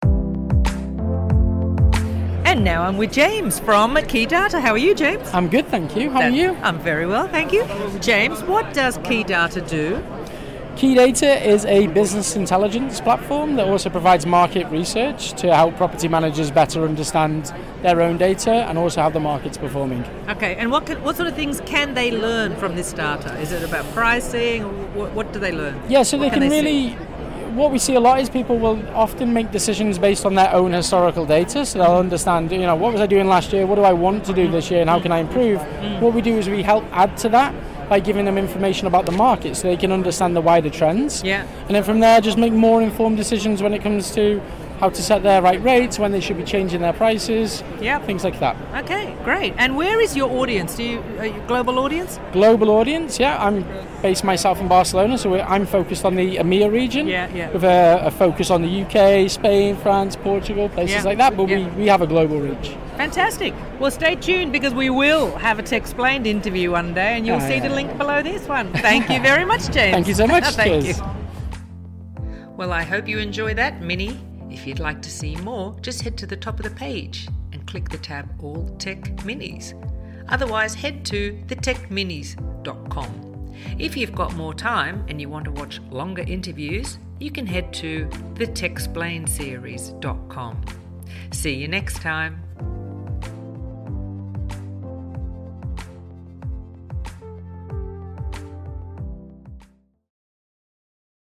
Location: The Short Stay Summit, London, April 2024.